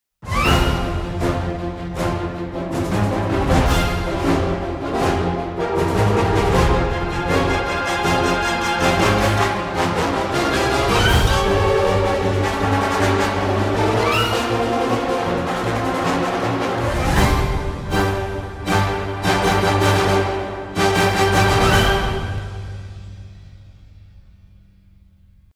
Cinematic brass library
• Everything you need for symphonic orchestral brass writing